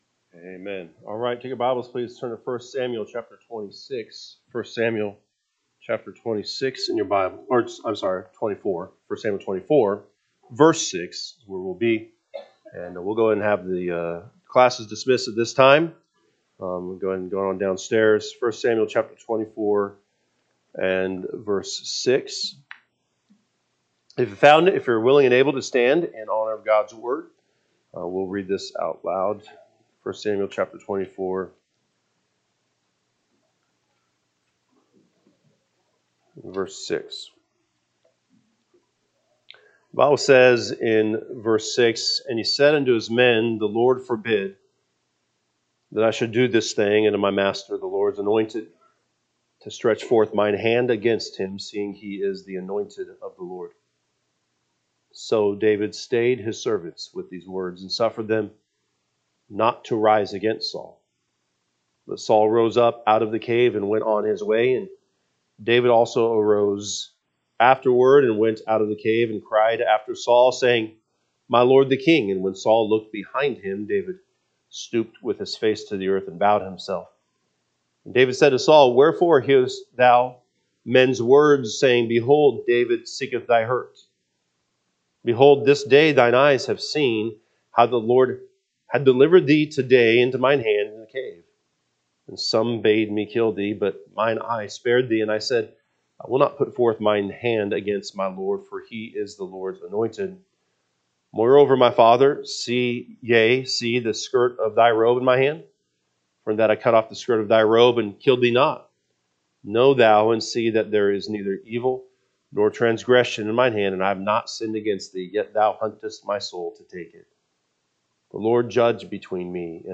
Sunday AM Message